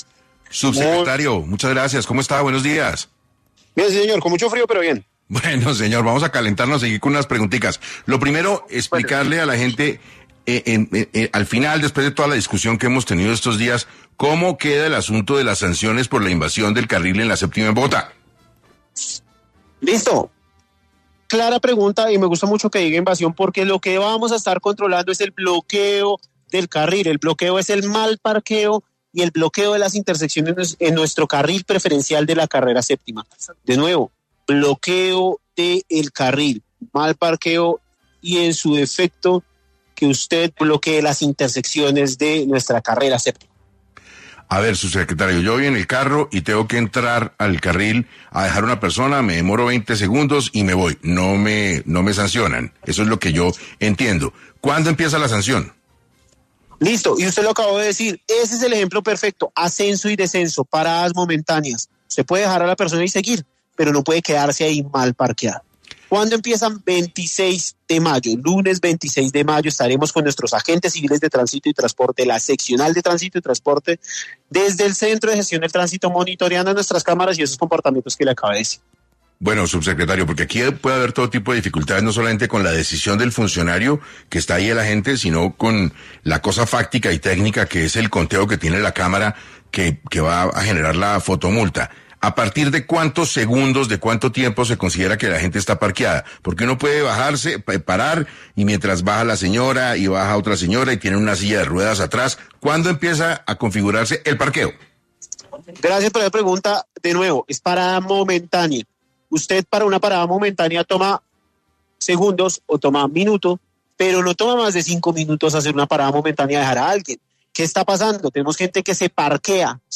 En entrevista concedida al programa 6AM John González, Subsecretario de Gestión de Movilidad de Bogotá, explicó los detalles claves del nuevo control sobre el uso del carril preferencial y las multas que enfrentarán quienes incumplan la medida.